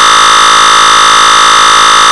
просто сигнал ПЧ